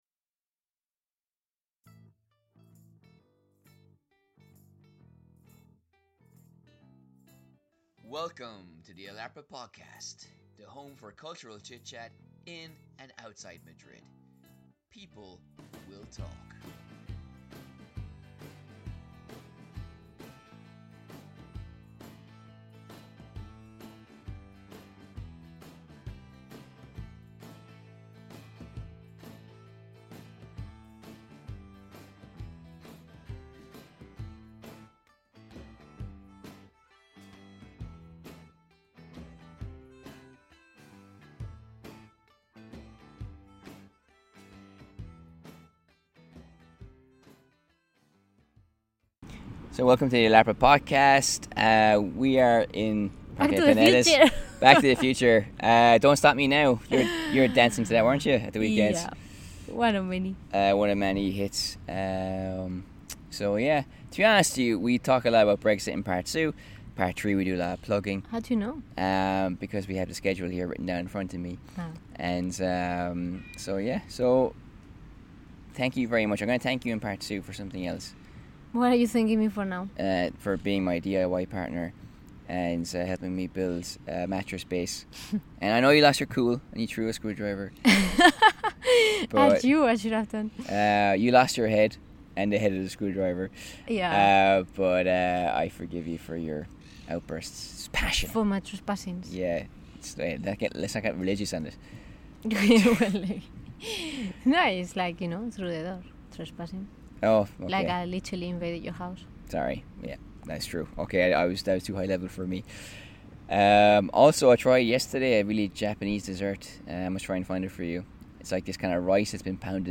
Broadcasting from the Planets Park in Mostoles, an update on this week's hottest topics (more Brexit shambles!)